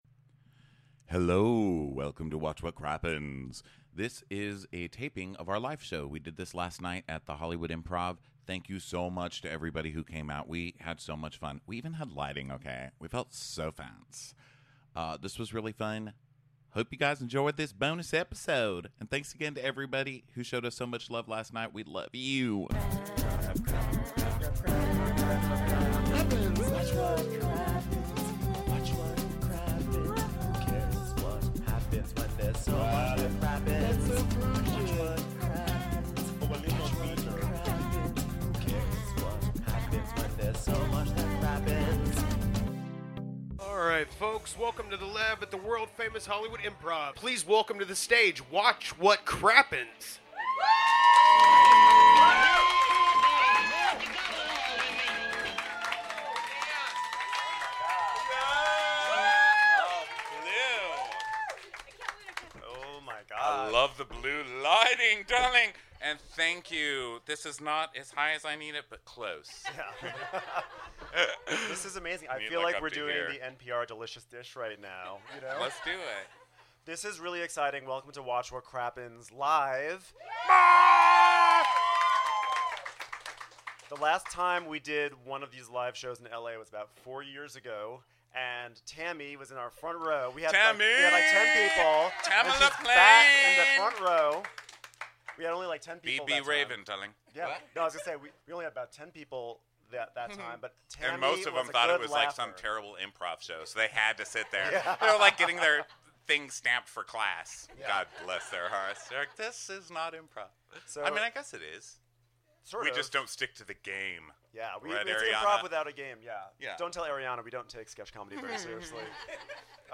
BONUS! Crappens Live at the Improv
We had an amazing time doing our live show at the Hollywood Improv last night.
Please enjoy this live bonus!!